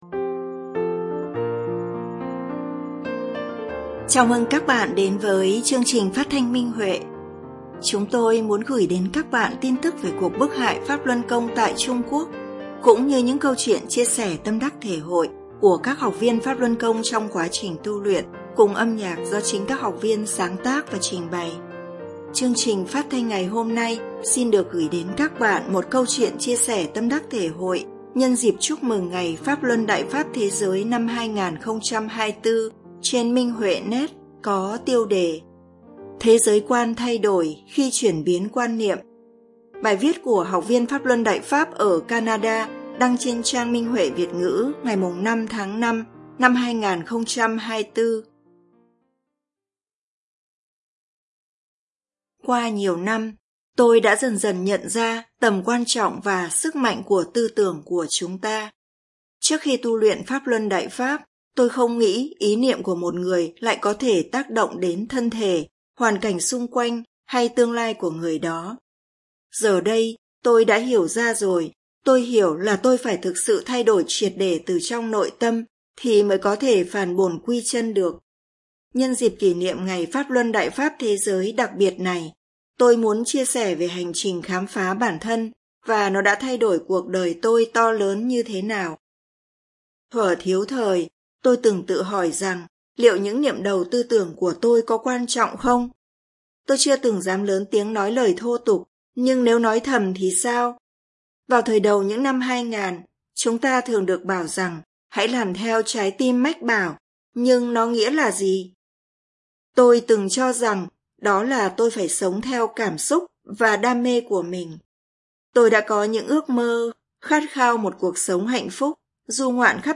Chương trình phát thanh số 01: Bài viết chia sẻ tâm đắc thể hội nhân dịp Chúc mừng Ngày Pháp Luân Đại Pháp Thế giới trên Minh Huệ Net có tiêu đề Thế giới quan thay đổi khi chuyển biến quan niệm, bài viết của đệ tử Đại Pháp người phương Tây ở Canada.